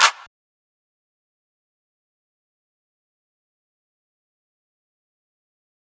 Clap (R.I.C.O).wav